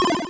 rotate_piece.wav